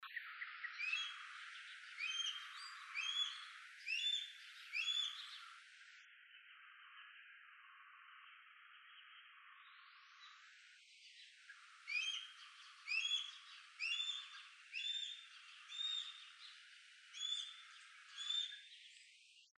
Harris´s Hawk (Parabuteo unicinctus)
Country: Bolivia
Location or protected area: Quillacollo
Condition: Wild
Certainty: Photographed, Recorded vocal